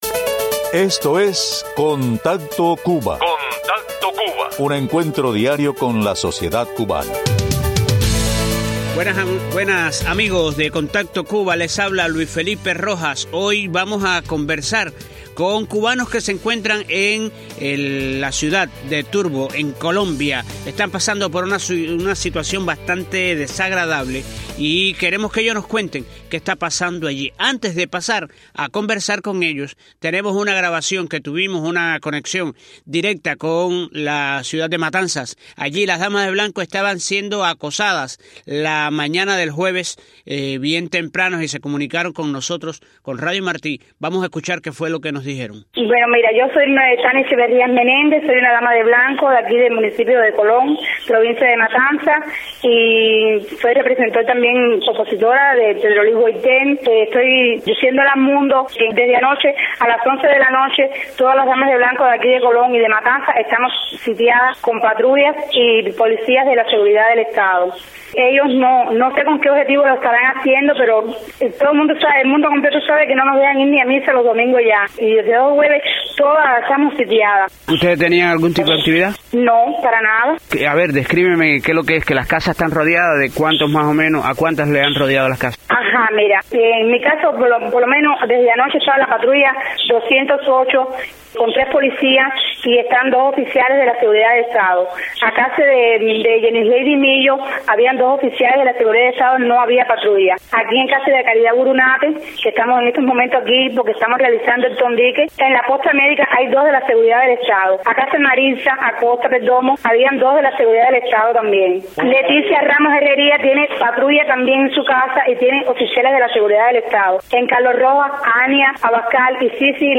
Conversación con dos cubanos que están varados en Turbo, Colombia